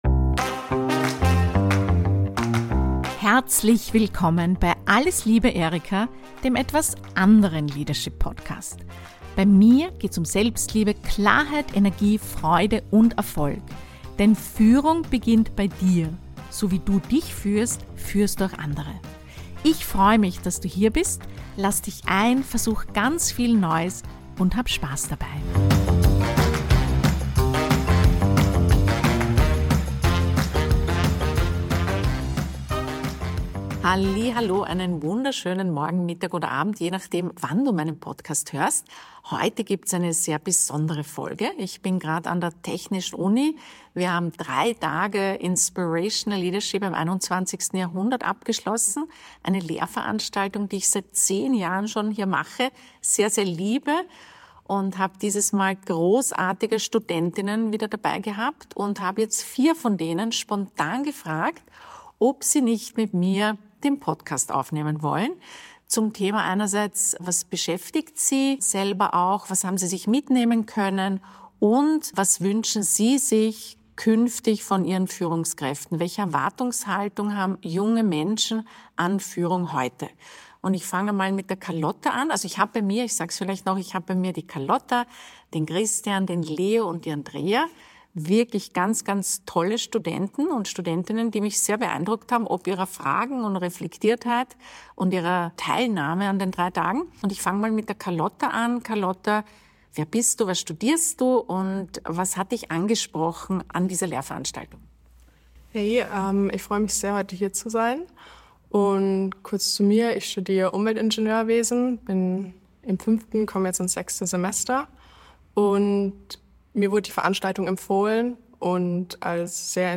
Wir tauchen ein in eine Fülle von Modellen, innovativen Ansätzen, praktischen Übungen und einem lebhaften Austausch, der nicht nur das Lernen bereichert, sondern auch inspirierende Perspektiven eröffnet. Ich hatte die Gelegenheit, vier von meinen 26 Teilnehmer:innen spontan zu befragen und ihre Eindrücke und Erfahrungen festzuhalten.